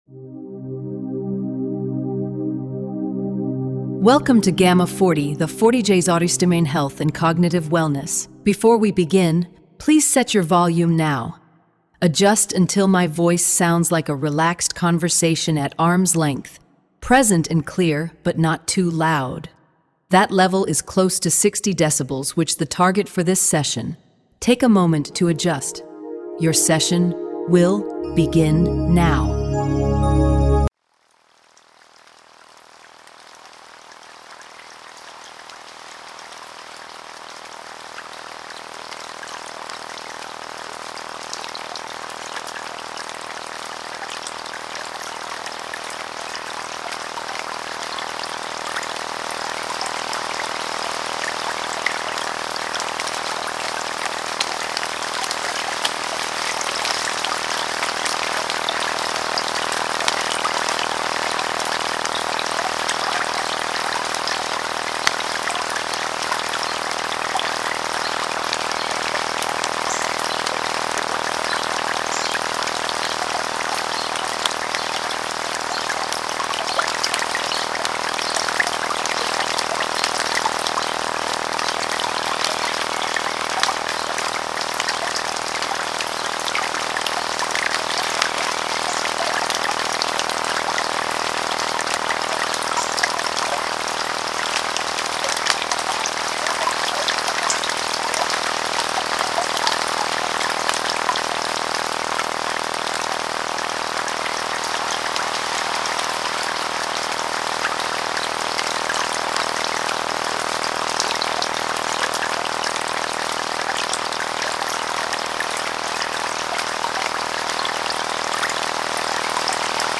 Gamma40 | 40 Hz Brain Health Audio
Nine 60-minute audio tracks delivering precisely timed 40 Hz auditory stimulation, based on the Wang et al. 2026 PNAS primate study.
rain_on_leaves.mp3